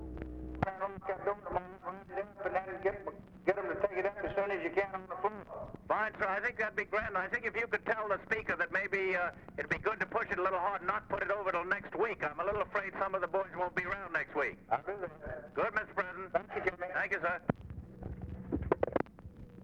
Conversation with JAMES ROOSEVELT, December 09, 1963
Secret White House Tapes